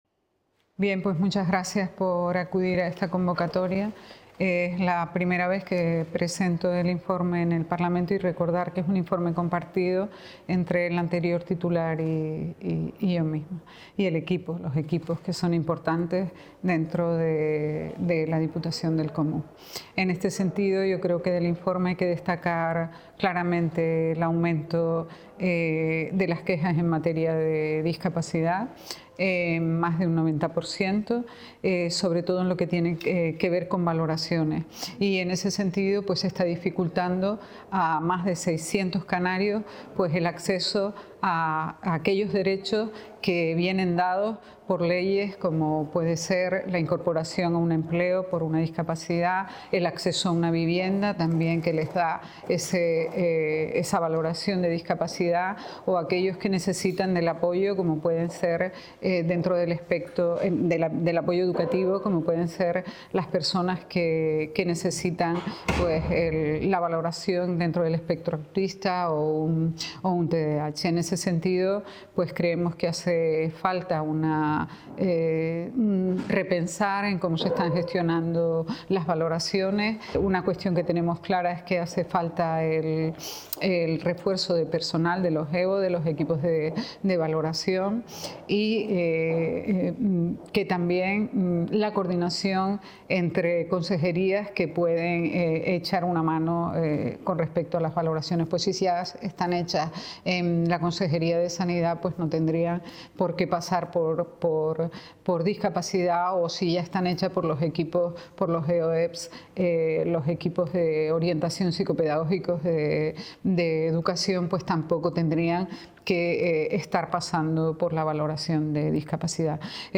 Entrevistas y declaraciones